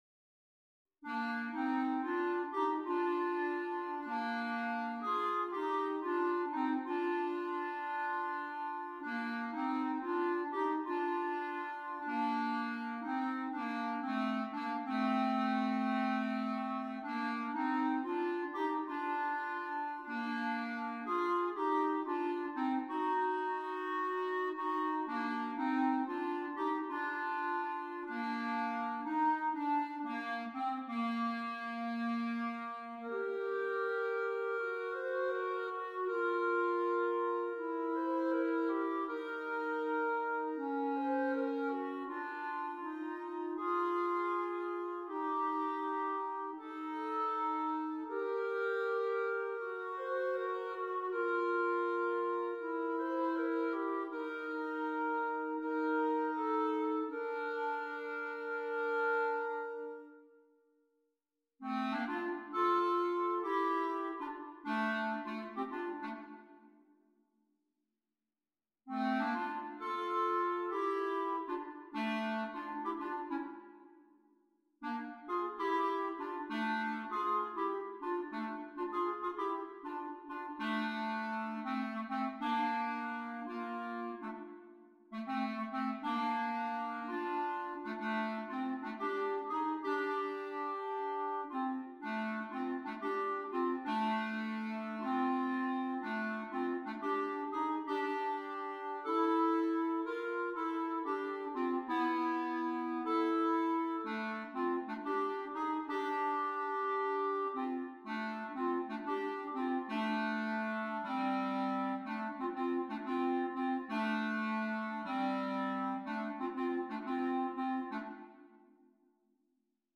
2 Clarinets
A varied assortment of styles is offered for your merriment.